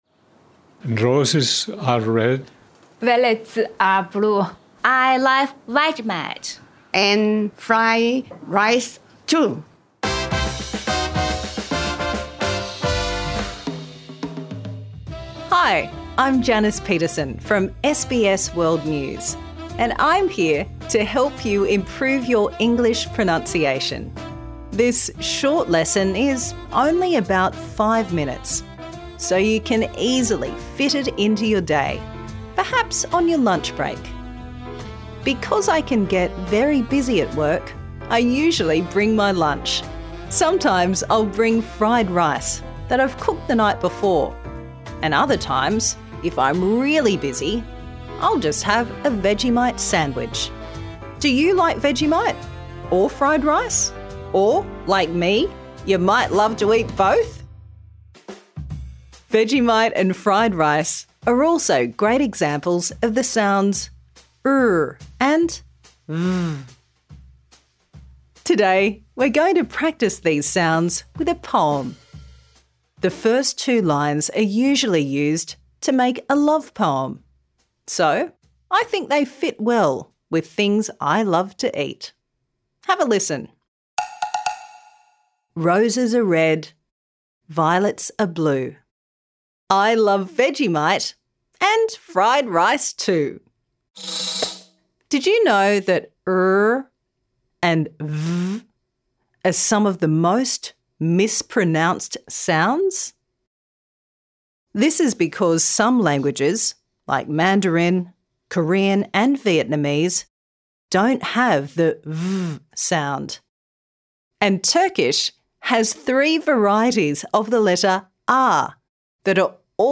Key Points Learning objectives: Can pronounce /r/ and /v/.
Minimal Pairs: /r/ ran, rest, real, rote, rain /v/ van, vest, veal, vote, vain.